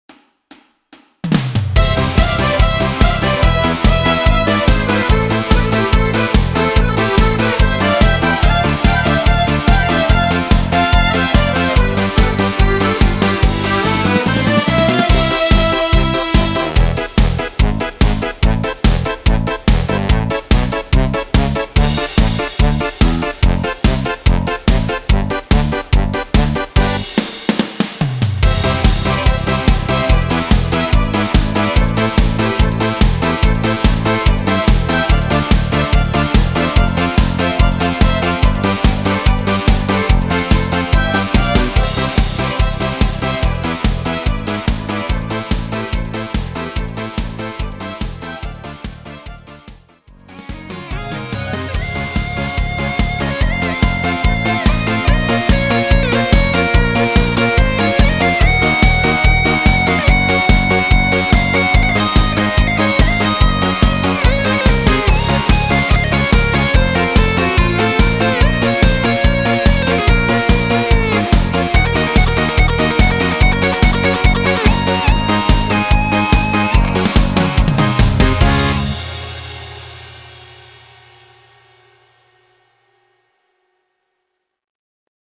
Disco Dance , Folk